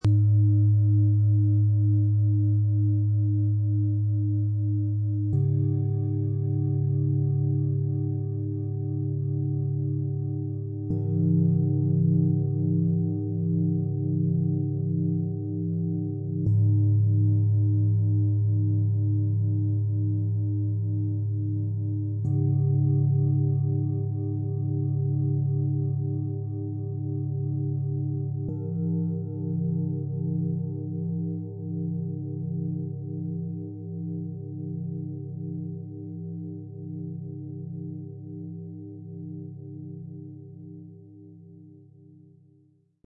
Mittleres Klangmassage Set „Loslassen - Auftanken - Ausrichten“ - Set aus 3 Klangschalen, Ø 17 - 22,6 cm, 2,47 kg
Größte Schale – Loslassen – Tief, warm, erdend
Diese Fuß-, Becken- und Bauchschale erzeugt ein tiefes, volles Fundament mit langem Nachklang.
Die Bauch- und Herzschale klingt klar und voll.
Die Schulter- und Kopfschale klingt hell, brillant und präsent.
Im Sound-Player - Jetzt reinhören lässt sich der Originalklang der drei Schalen direkt anhören. Jede zeigt ihre eigene Tonfarbe – vom tiefen Loslassen bis zur klaren Neuausrichtung.
MaterialBronze